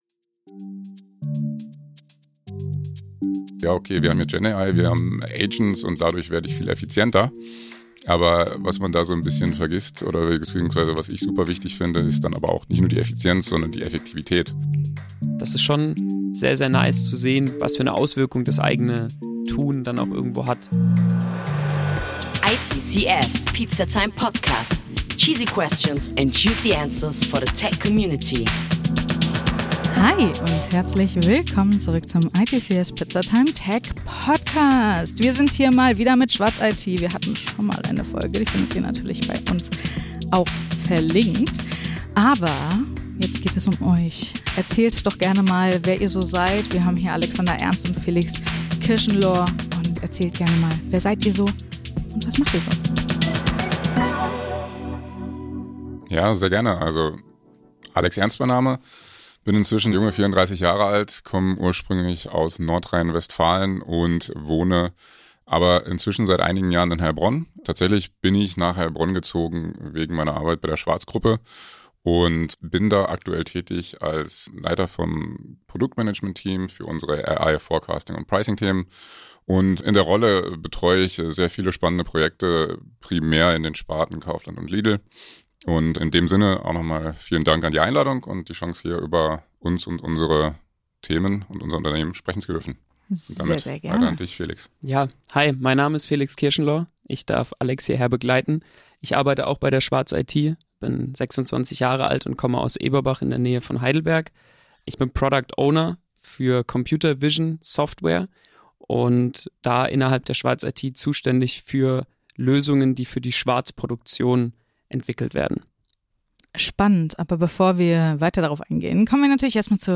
ITCS Pizzatime steht für Snackable Content mit authentischen Einblicken in die Tech- und IT-Branche und das alle 2 Wochen frisch serviert! Seid dabei und lasst Euch inspirieren, wenn wir Brancheninsider aus den verschiedensten Bereichen zu den aktuellsten und spannendsten Tech- und IT-Trends befragen.